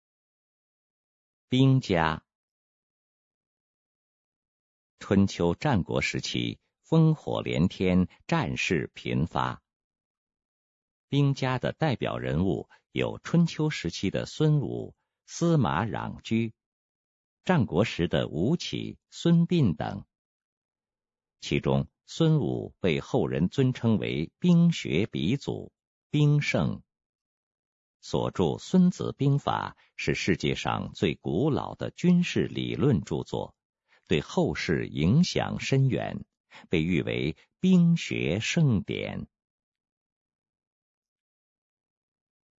语音导览